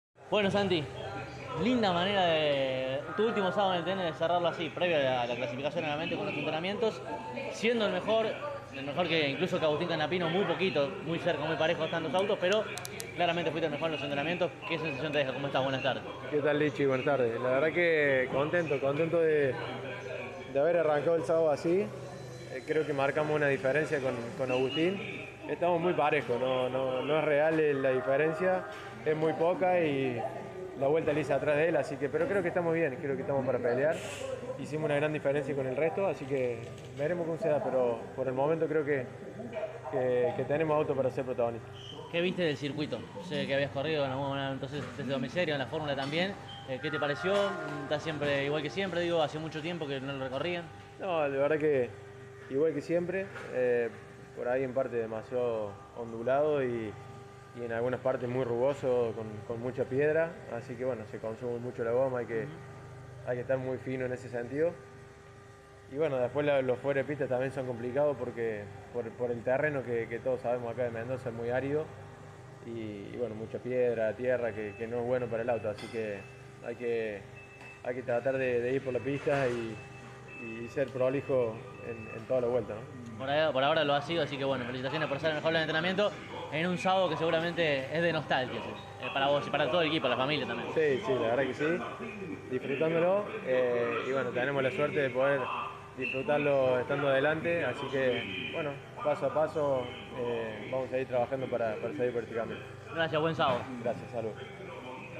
en diálogo con CÓRDOBA COMPETICIÓN